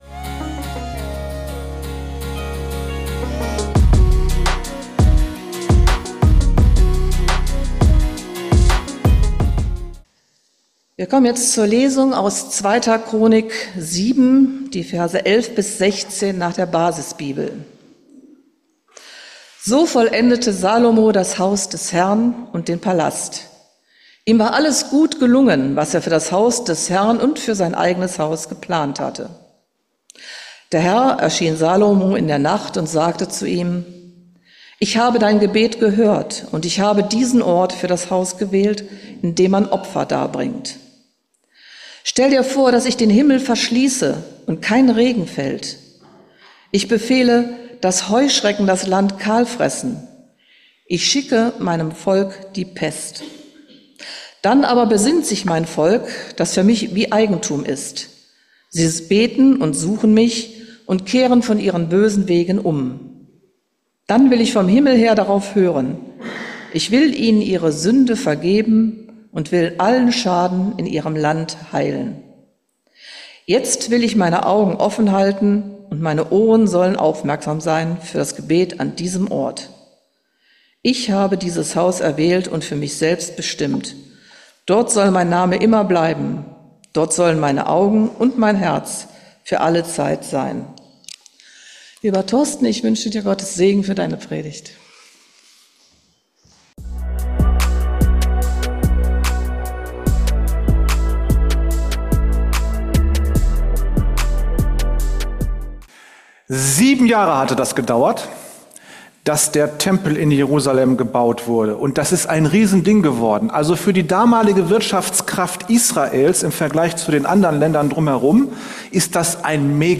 Gebet - wozu ist das gut? ~ Geistliche Inputs, Andachten, Predigten Podcast